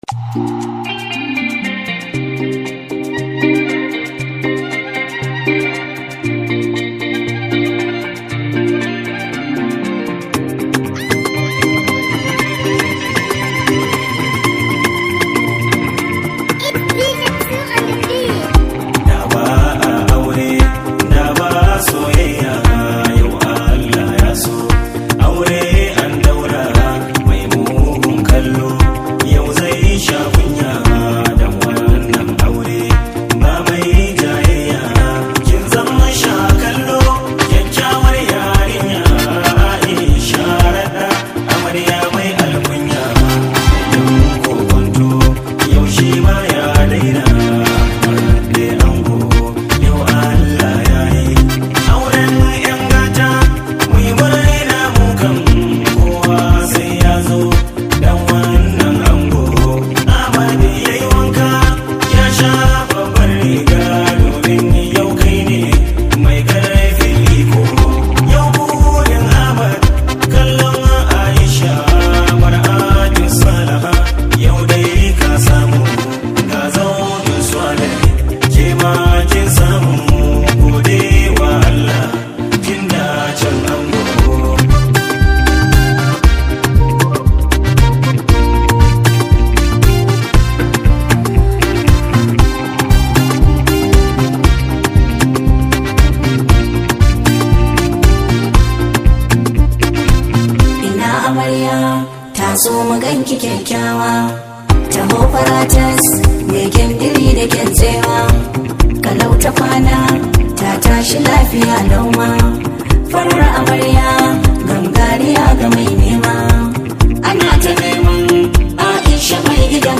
Waƙar Farin Ciki
waƙa ta murna.